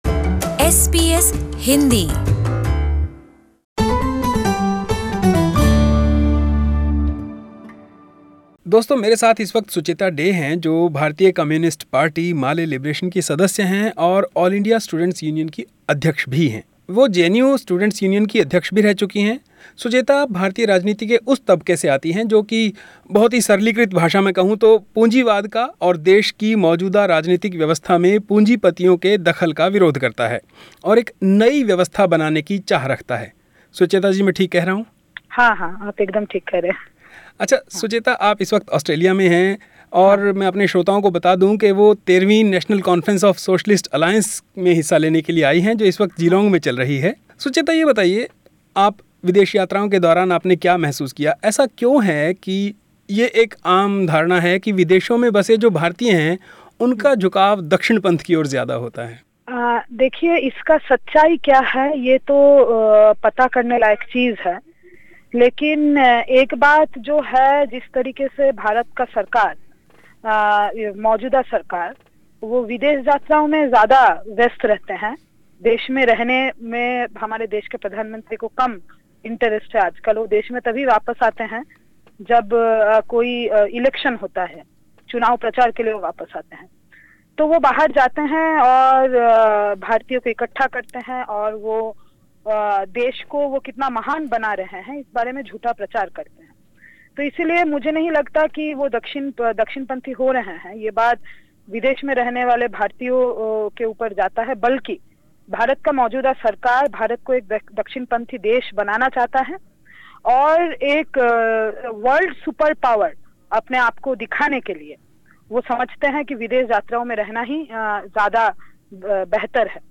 The complete interview